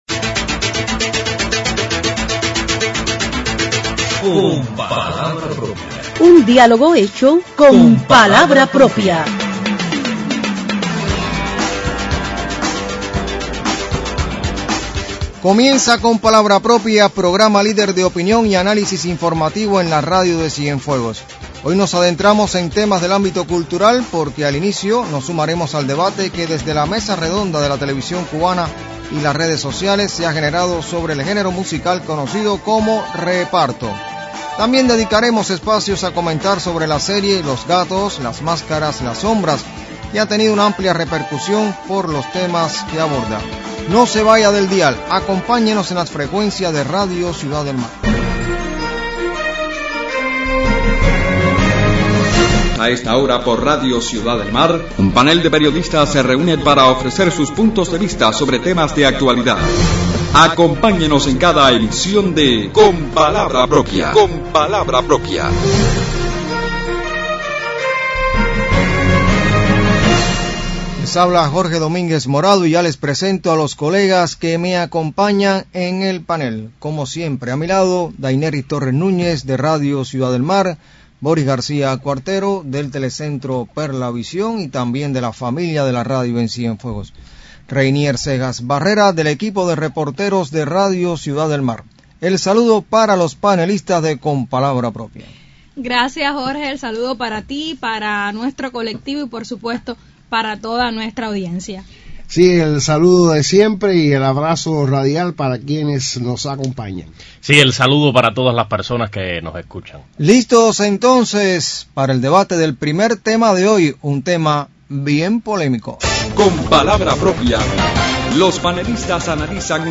Sobre la repercusión que ha tenido en redes sociales la Mesa Redonda de la Televisión Cubana que abordó el impacto del género musical conocido como reparto comentan los panelistas de Con palabra propia en la emisión del sábado 5 de abril.